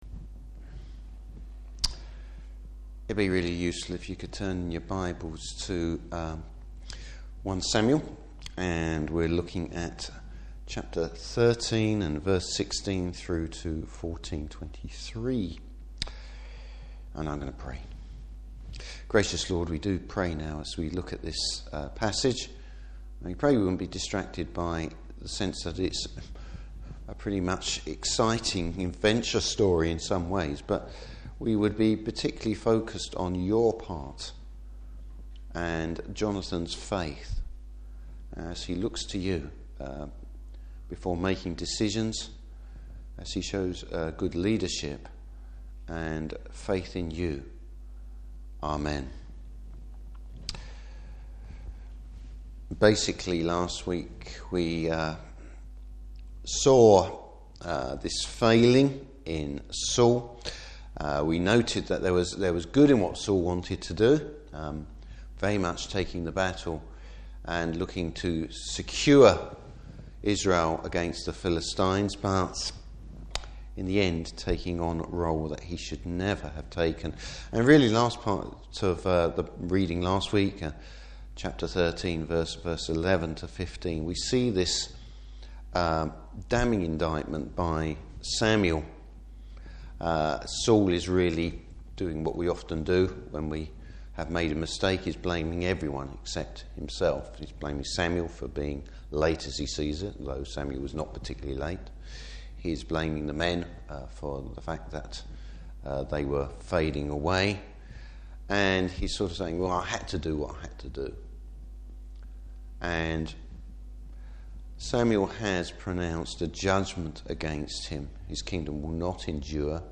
Service Type: Evening Service Jonathan demonstrates leadership and faith.